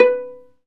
Index of /90_sSampleCDs/Roland L-CD702/VOL-1/STR_Viola Solo/STR_Vla Pizz